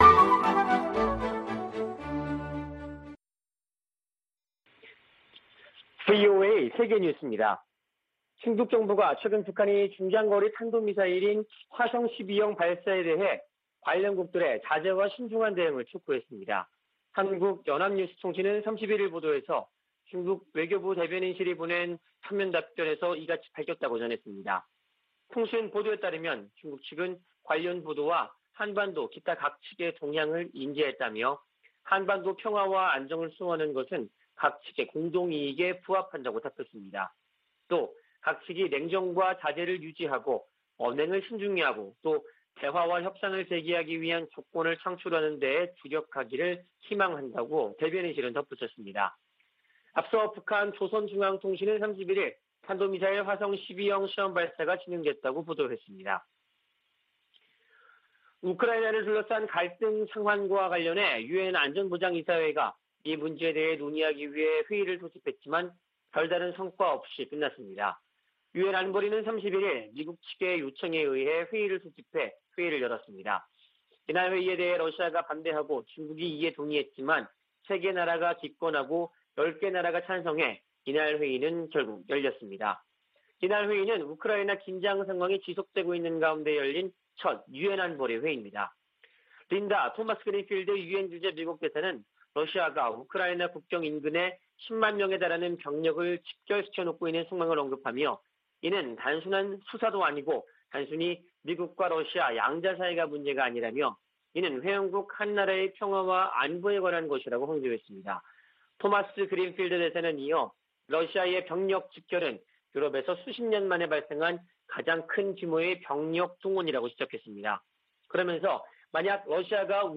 VOA 한국어 아침 뉴스 프로그램 '워싱턴 뉴스 광장' 2021년 2월 1일 방송입니다. 북한이 30일 발사한 미사일이 중거리 탄도미사일인 '화성-12형'이었다고 공개했습니다. 미 국무부는 4년 만에 최대 수위의 미사일 도발을 감행한 북한을 규탄했습니다.